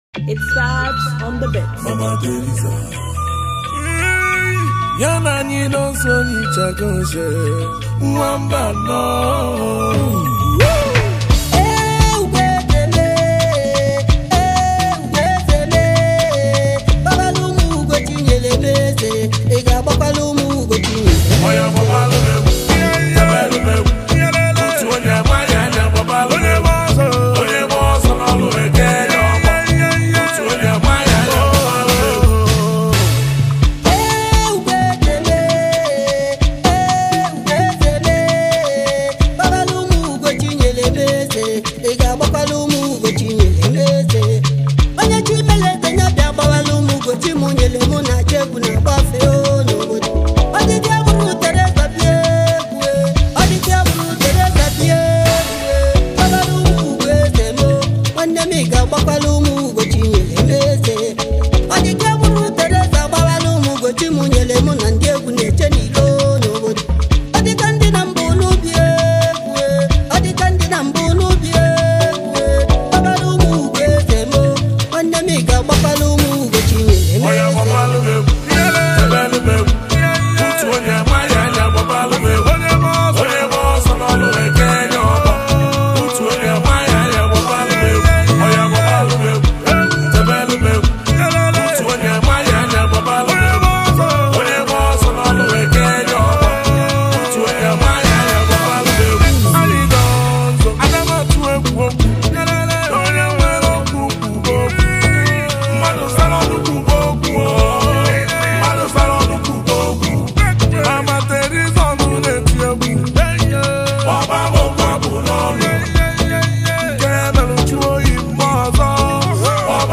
Nigeria talented highlife music singer and songwriter